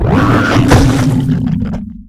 CosmicRageSounds / ogg / general / combat / creatures / alien / he / die1.ogg
die1.ogg